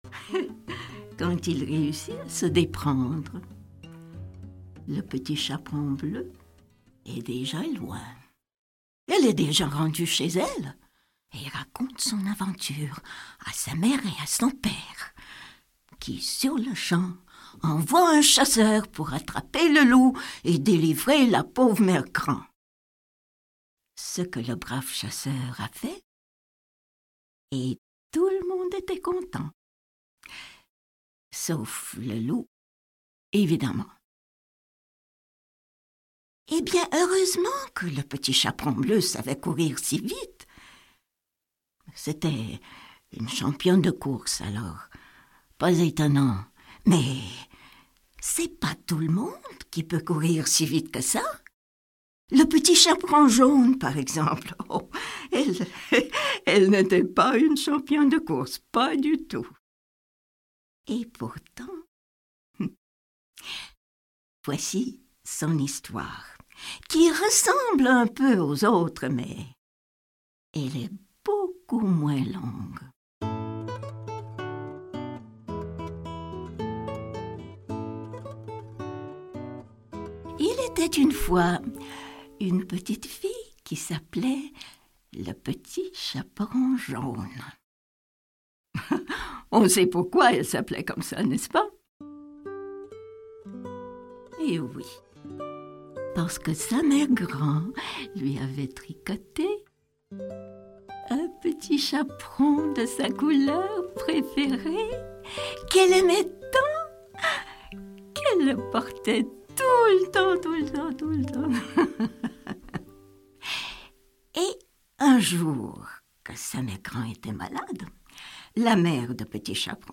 Les Éditions Alexandre Stanké sont fièrs de vous présenter le premier volume de la collection " LES CONTES DE FANFRELUCHE " racontée et illustrée par nulle autre que Kim Yaroshevskaya. Dans ce premier volume, Fanfreluche nous raconte l'histoire du Petit Chaperon Bleu et du Petit Chaperon Jaune. Accompagnée tout simplement d'une guitare acoustique, la voix enveloppante et chaleureuse de Fanfreluche promet de garder les tout petits enfants attentifs et fascinés jusqu'à la dernière page.